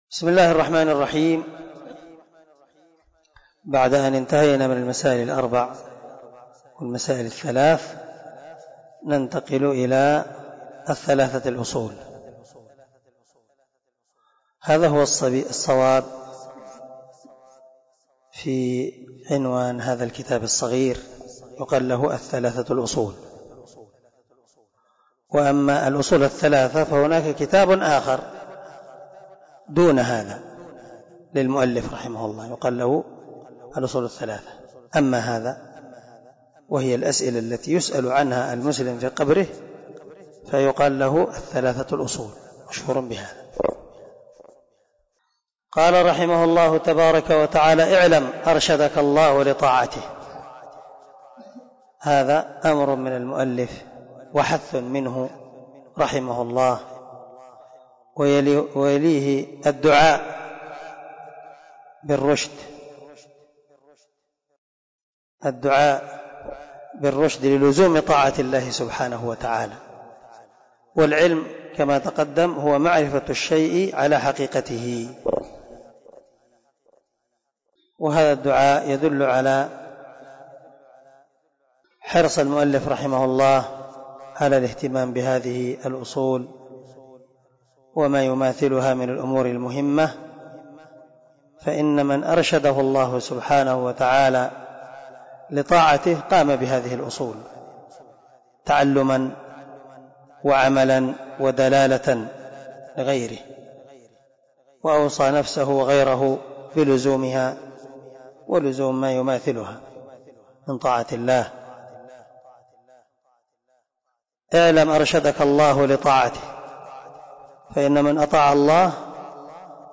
🔊 الدرس 7 من شرح الأصول الثلاثة